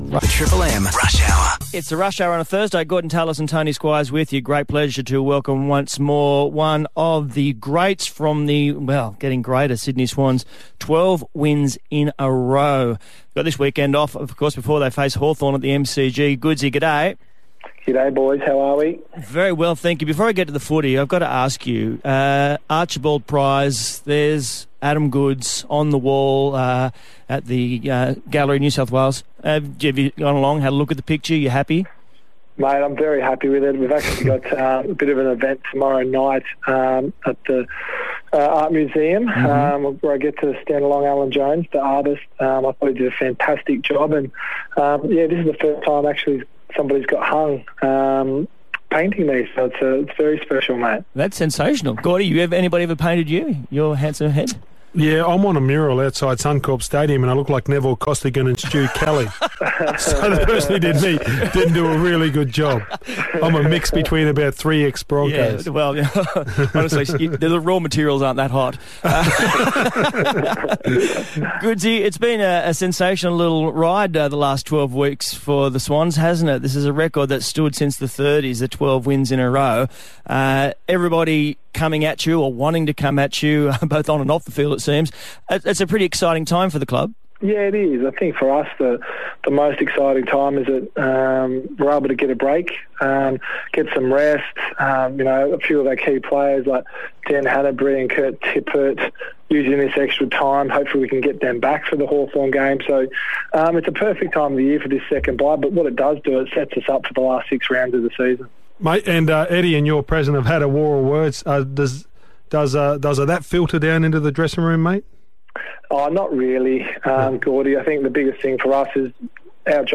Sydney Swans champion Adam Goodes appeared on Triple M Sydney's Rush Hour program with Tony Squires and Gorden Tallis on Thursday July 17, 2014